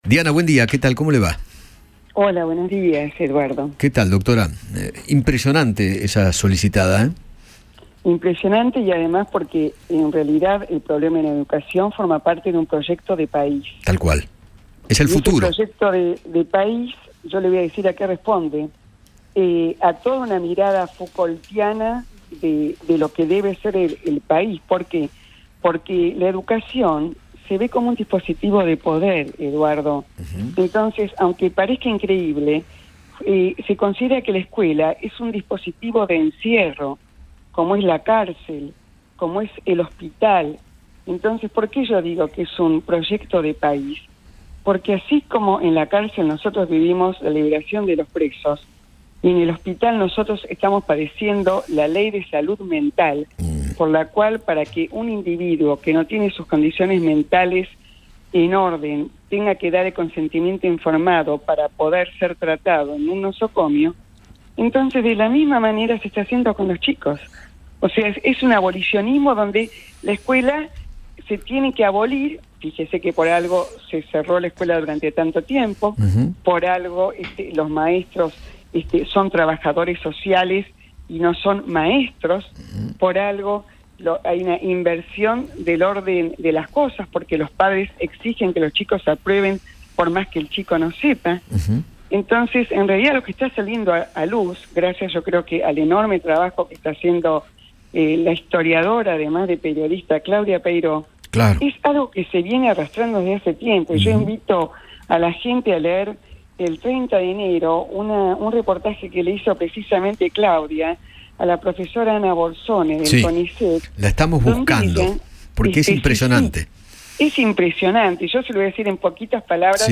La filósofa Diana Cohen Agrest dialogó con Eduardo Feinmann sobre la solicitada firmada por grandes pensadores argentinos para expresar su preocupación por la crisis que viven las escuelas argentinas.